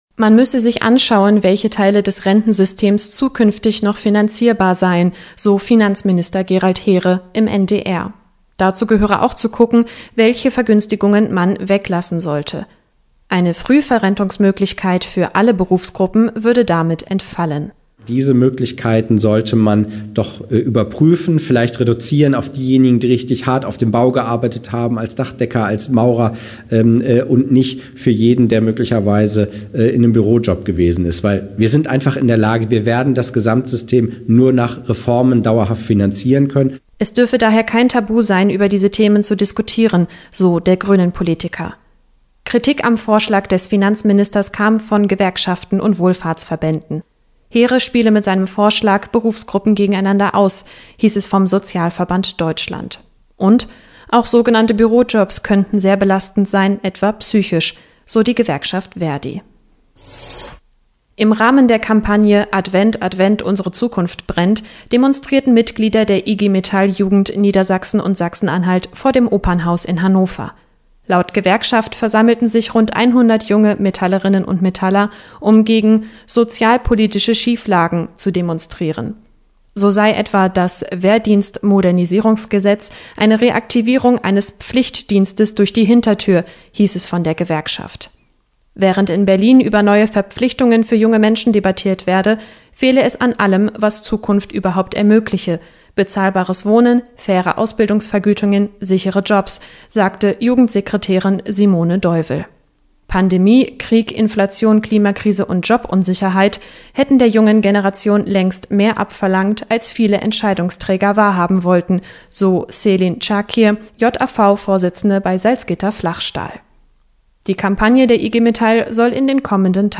Diese und weitere Meldungen aus unserem Bundesland